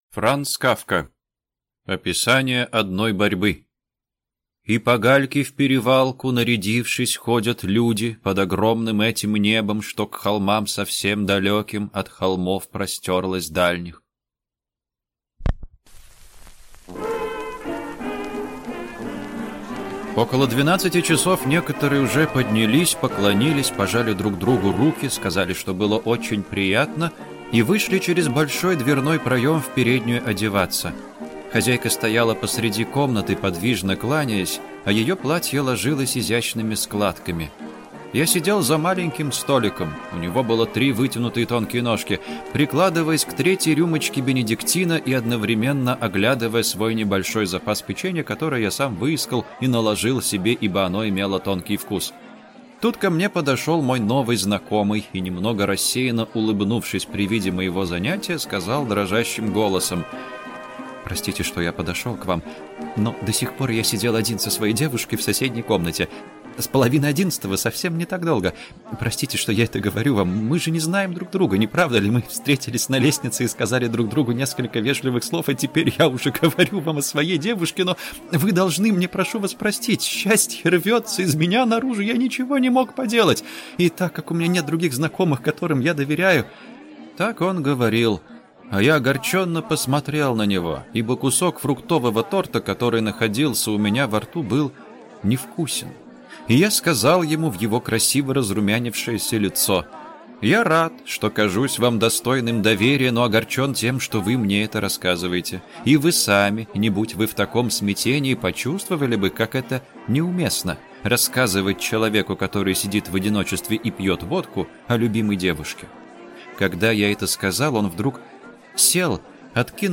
Аудиокнига Описание одной борьбы | Библиотека аудиокниг